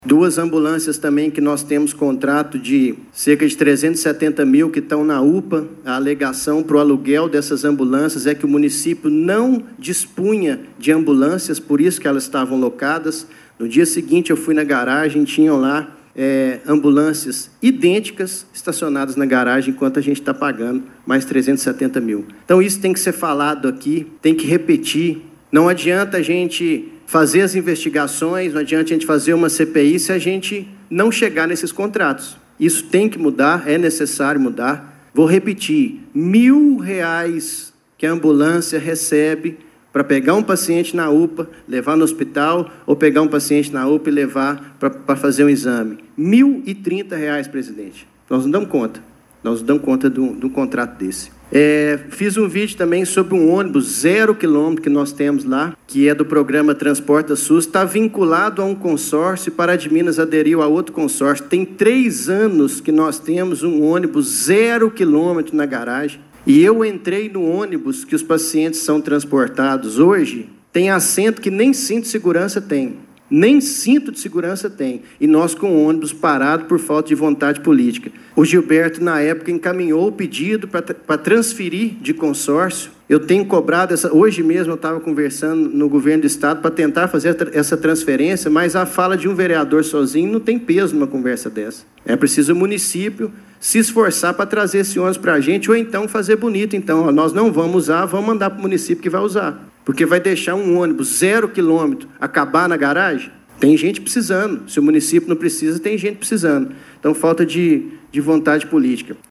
O vereador Leonardo Xavier Assunção Silva (Novo), autor da proposta de abertura da investigação, utilizou seu tempo na tribuna para atualizar os trabalhos da Comissão Parlamentar de Inquérito (CPI), instaurada em 18 de novembro de 2025.